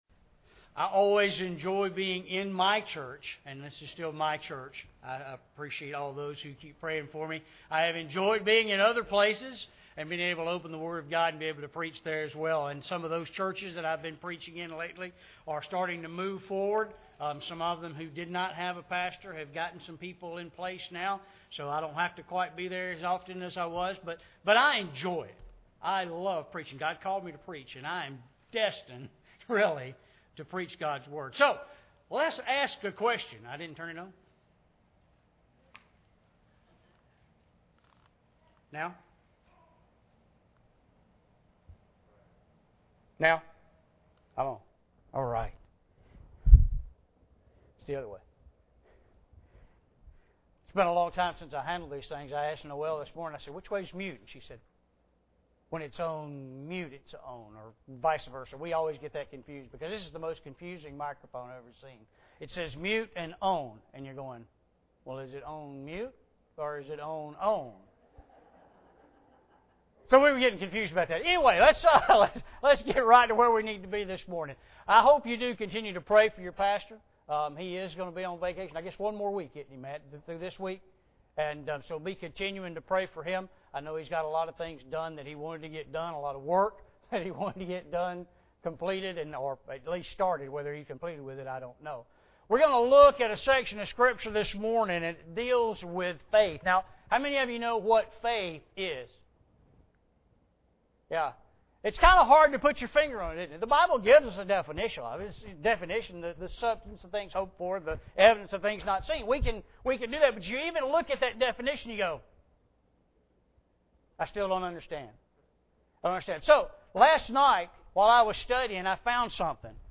Mark 5:21-34 Service Type: Sunday Morning Bible Text